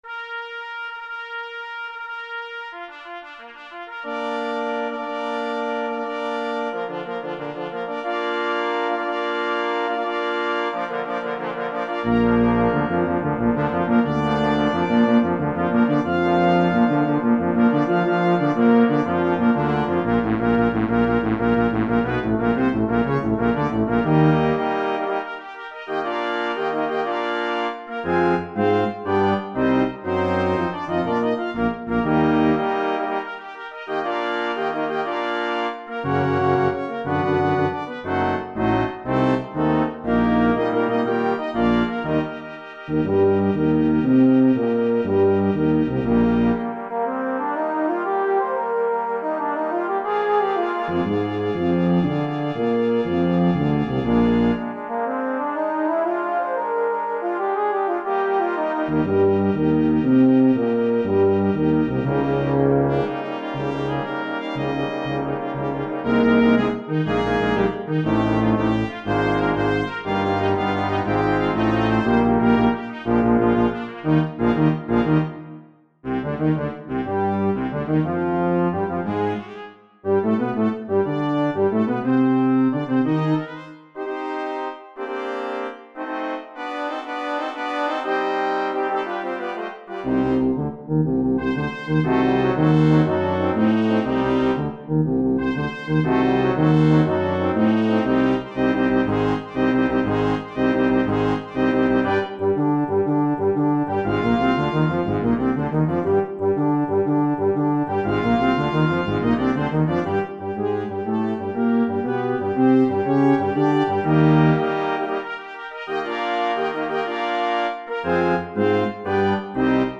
3 Trumpets
2 Horns in F
2 Trombones
Euphonium
Tuba
for Brass Nonet